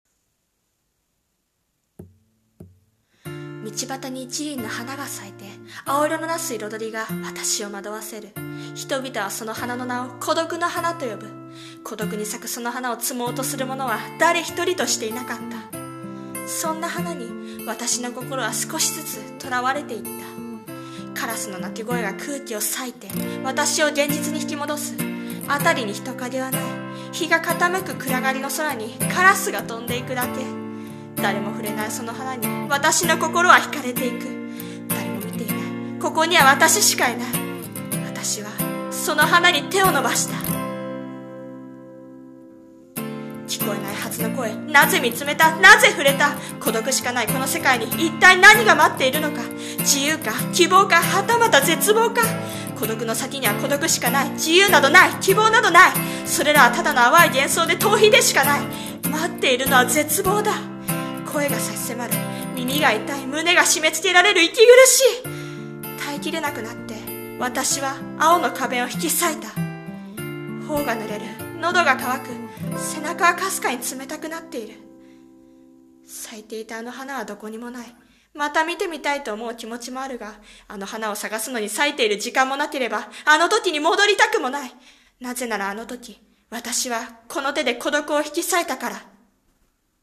さんの投稿した曲一覧 を表示 【声劇台本】サイテ…サイテ…サイタ。